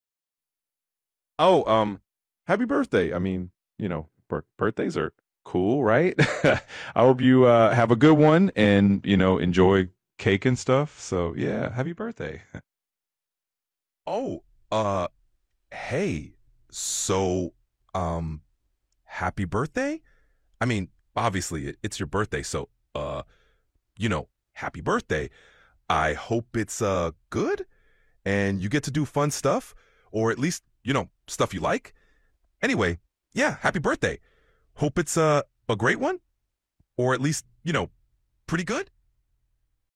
The new voice mode allows for real-time, back-and-forth conversations, almost like talking to a real person. It responds with emotion, nuance, and natural pacing.